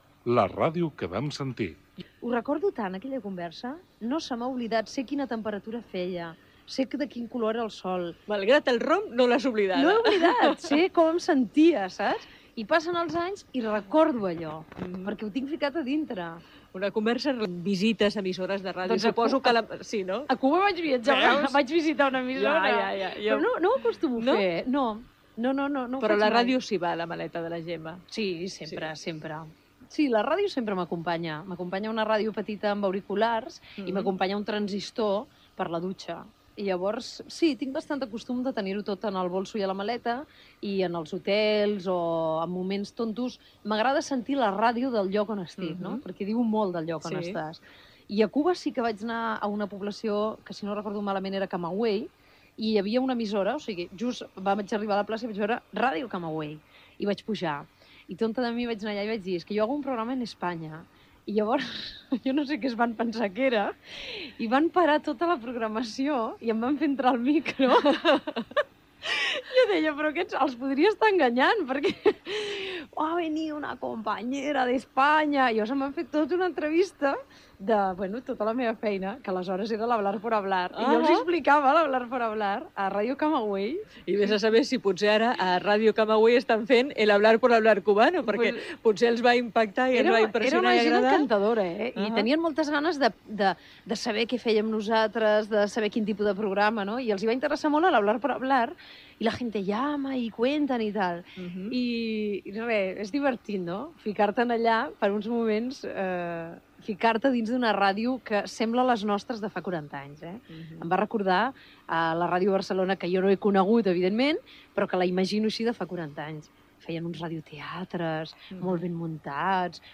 Indicatiu del programa, entrevista a Gemma Nierga. S'hi parla de la seva relació amb la ràdio quan viatja i d'alguns llocs que ha visitat o que hi vol anar